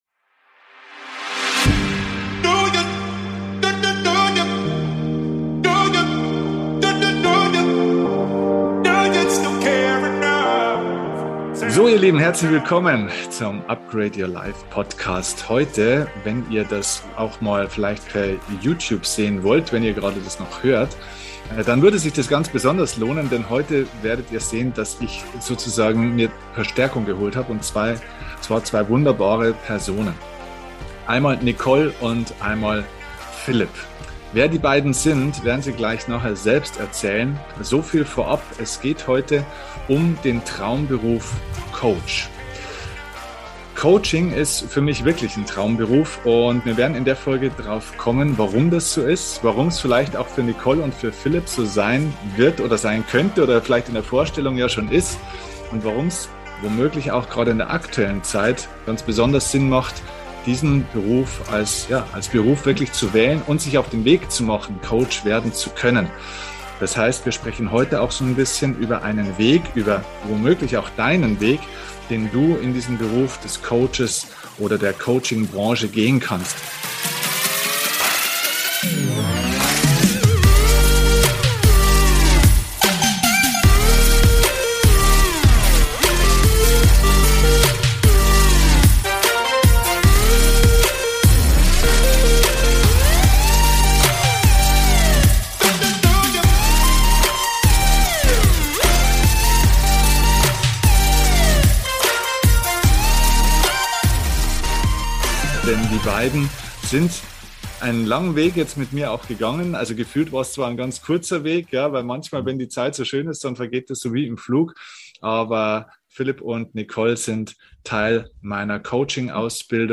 Heute sind zwei Teilnehmer und Absolventen zu Gast im UPGRADE YOUR LIFE Podcast und erzählen von ihrer Erfahrung aus der Ausbildung.